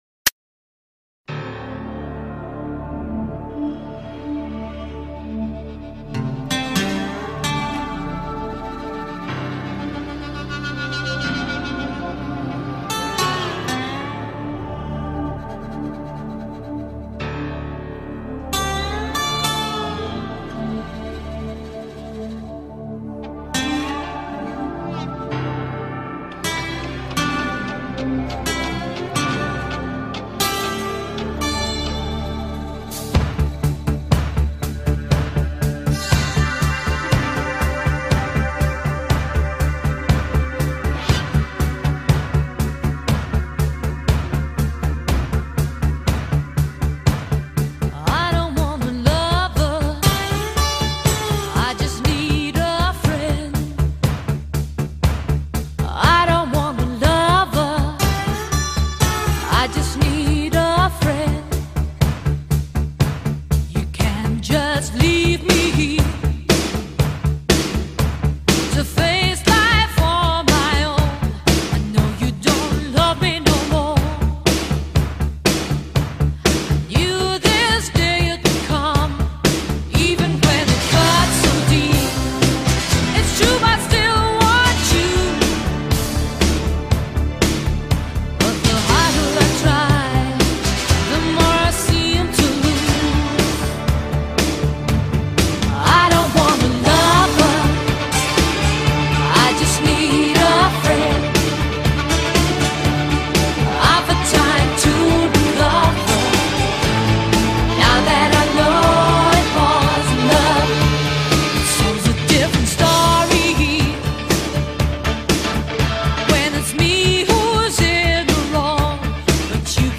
120 Bpm - Key : D